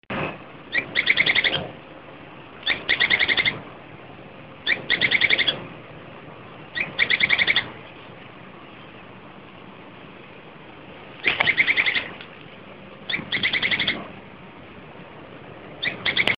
Pijuí Plomizo (Synallaxis spixi)
Nombre en inglés: Spix´s Spinetail
Localidad o área protegida: Reserva Ecológica Costanera Sur (RECS)
Condición: Silvestre
Certeza: Vocalización Grabada